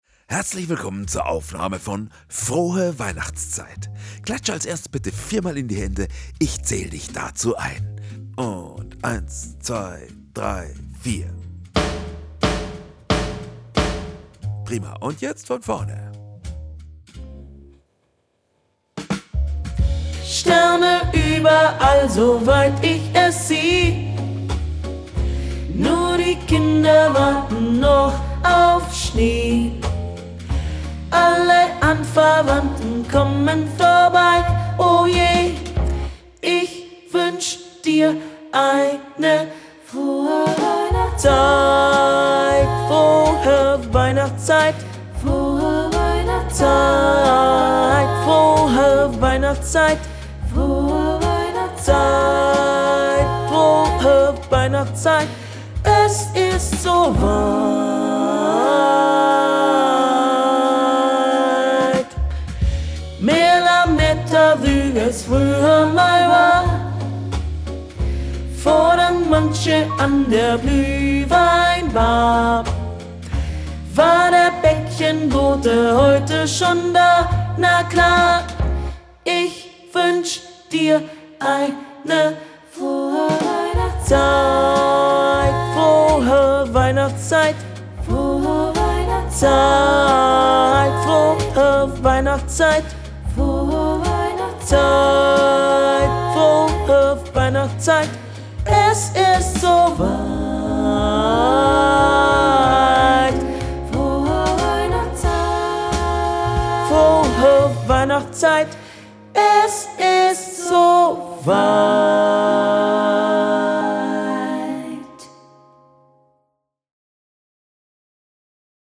Playback für Video - Alt
Frohe_Weihnachtszeit - Playback fuer Video - Alt.mp3